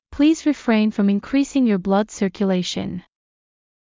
ﾌﾟﾘｰｽﾞ ﾘﾌﾚｲﾝ ﾌﾛﾑ ｲﾝｸﾘｰｼﾞﾝｸﾞ ﾕｱ ﾌﾞﾗｯﾄﾞ ｻｰｷｭﾚｲｼｮﾝ